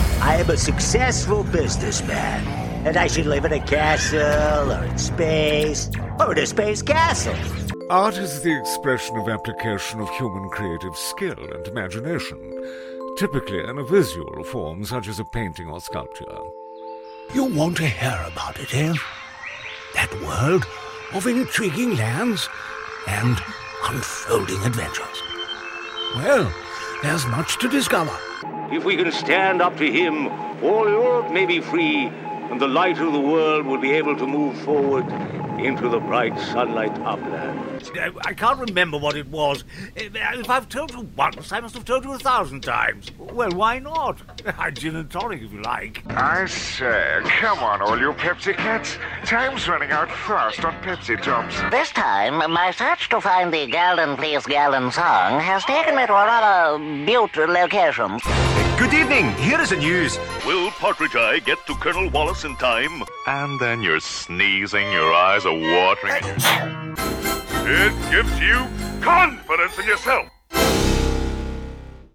He has a huge range of character voices and a wide range of accents suitable for gaming and animation.
• Male